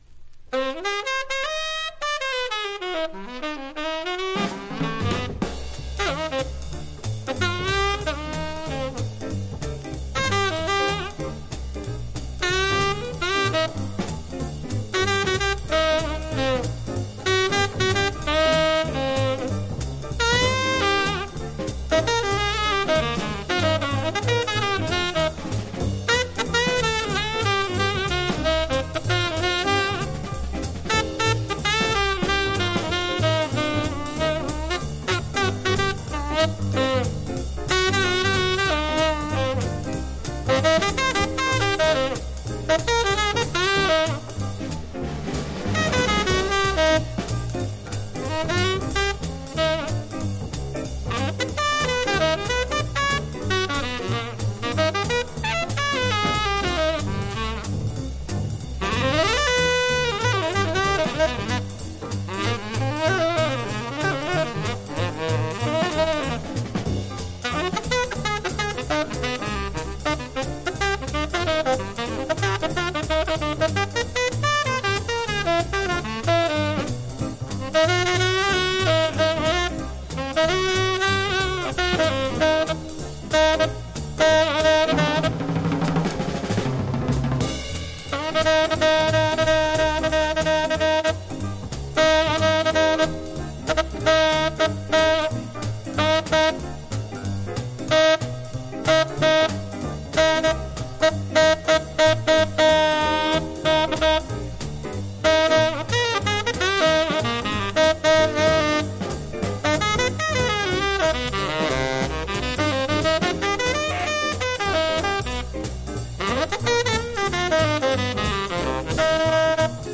Genre US JAZZ